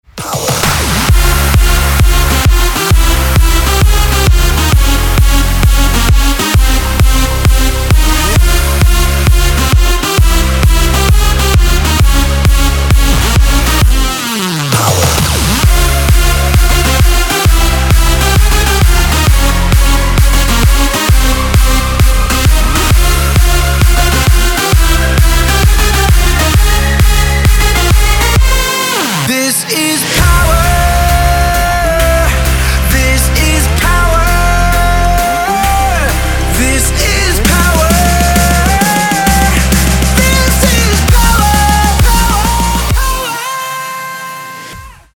• Качество: 192, Stereo
мужской вокал
dance
Electronic
EDM
club
Big Room